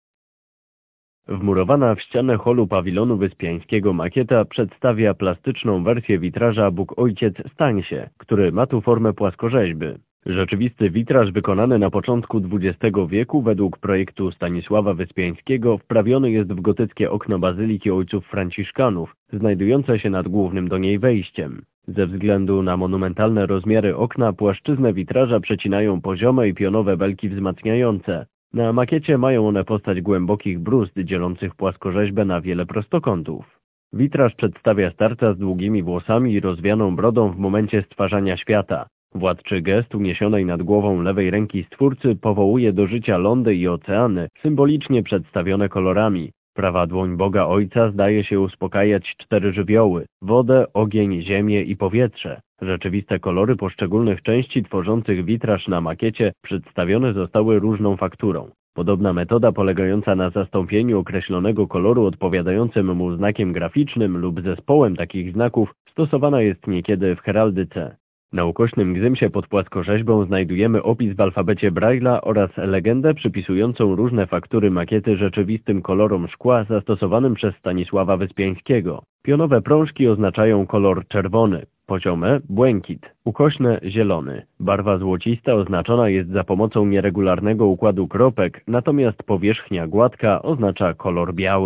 Tekst do odsłuchania (mp3)